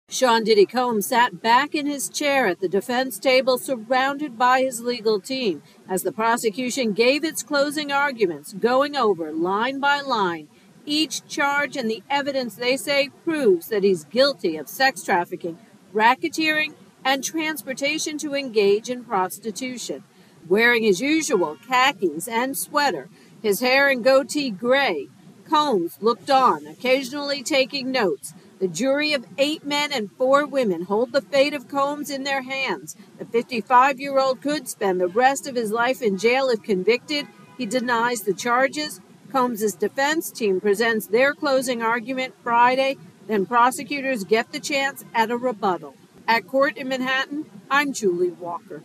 reports from court on the prosecutions closing arguments in the Sean 'Diddy' Combs case.